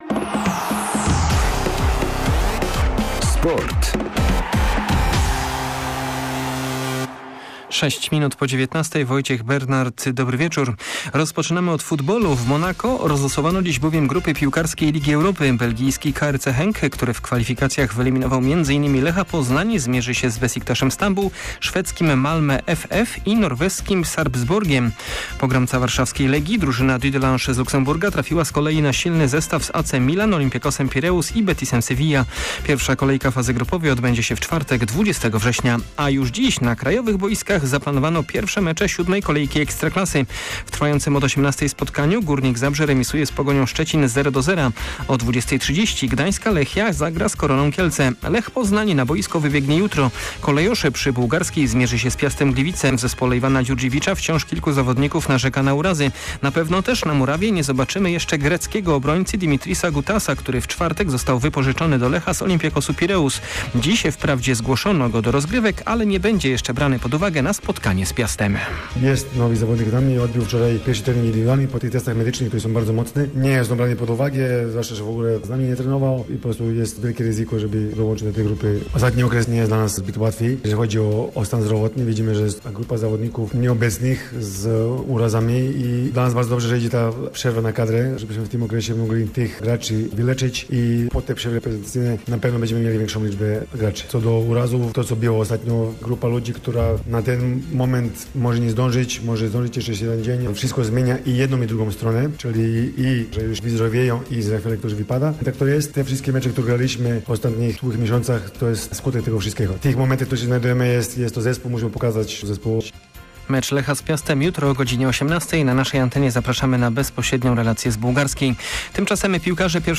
31.08 serwis sportowy godz. 19:05